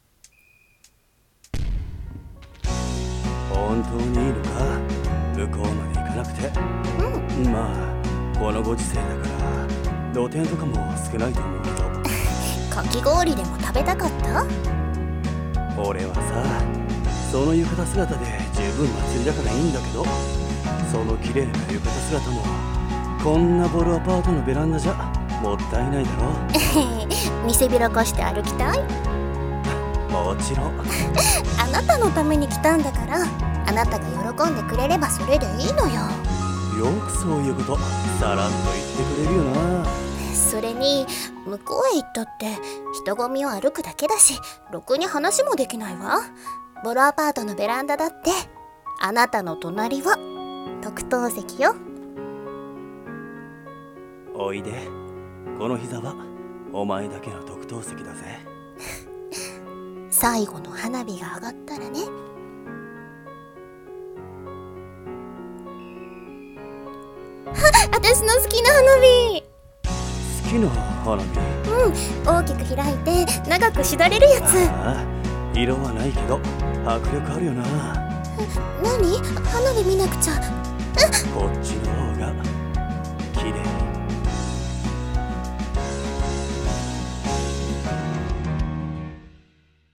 【声劇】特等席